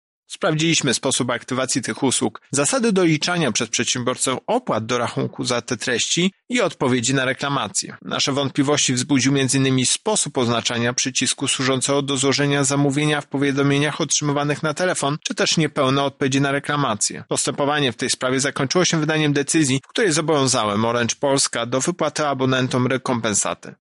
Otrzymywaliśmy skargi od konsumentów, którzy byli zaskoczeni wyższym rachunkiem i nie wiedzieli za co zostały naliczone opłaty – mówi Tomasz Chróstny, Prezes UOKiK: